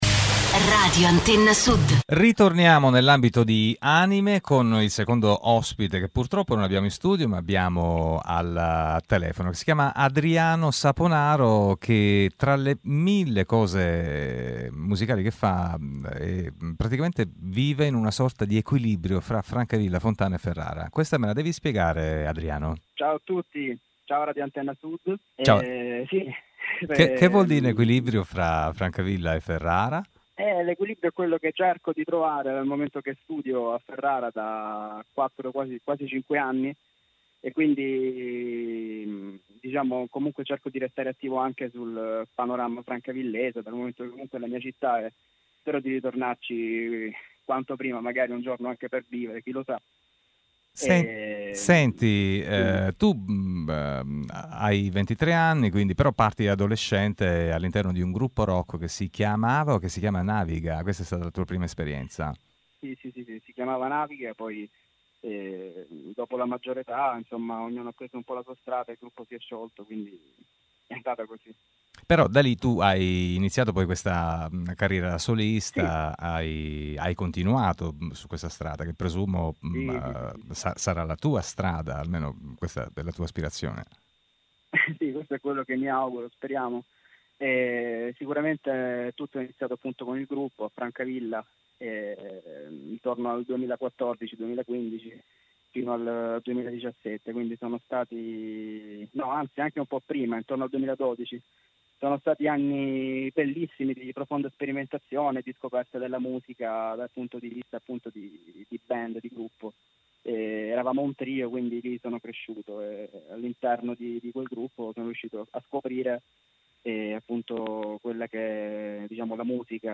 ANIME - TALENTI EMERGENTI ... al telefono